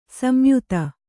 ♪ samyuta